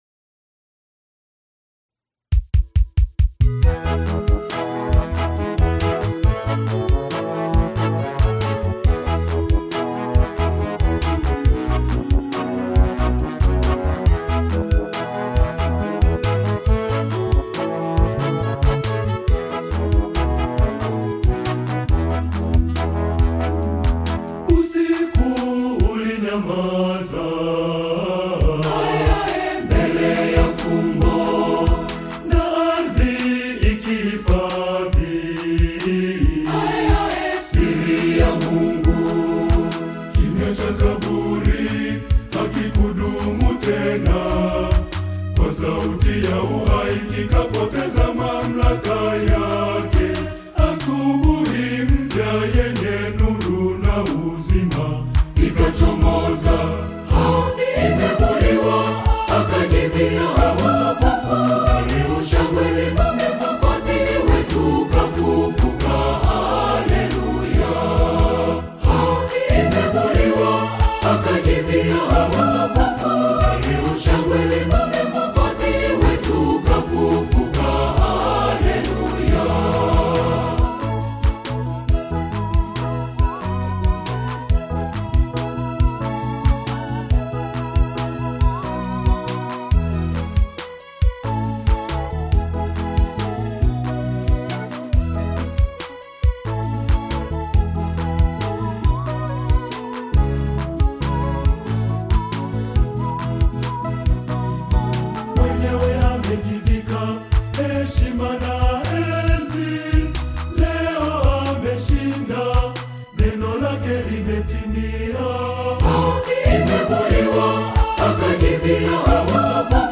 With its heartfelt lyrics and soulful melodies